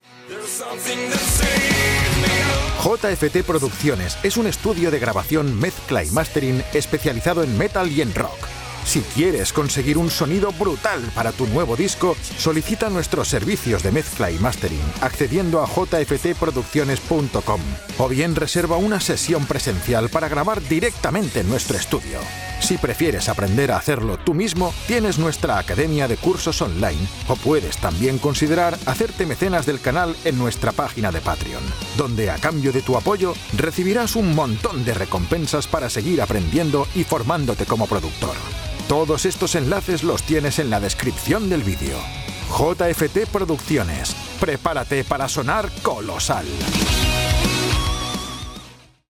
Mikrofon: Neumann TLM-103
Im mittleren Alter
Bass